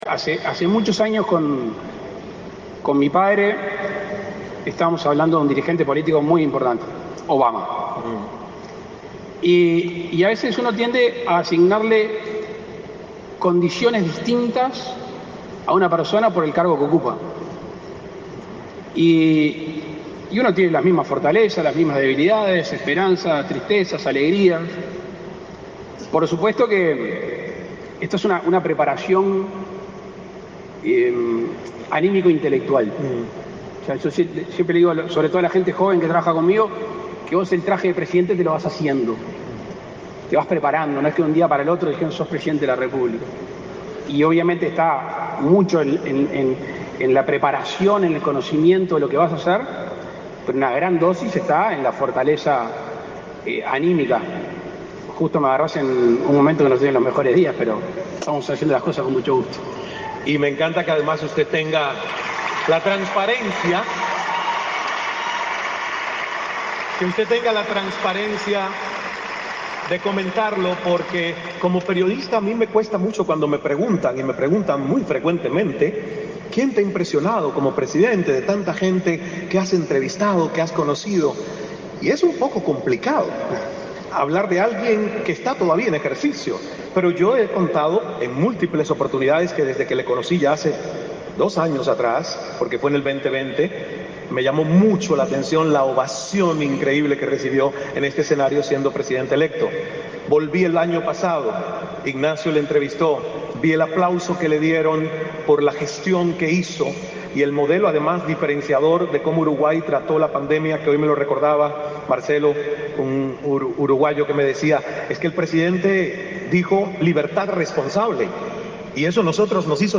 Palabras del presidente de la República, Luis Lacalle Pou
Palabras del presidente de la República, Luis Lacalle Pou 07/10/2022 Compartir Facebook X Copiar enlace WhatsApp LinkedIn El presidente de la República, Luis Lacalle Pou, participó, este 7 de octubre, en una nueva edición del America Business Forum, un evento internacional que reúne a líderes de la región, en el Centro de Convenciones de Punta del Este.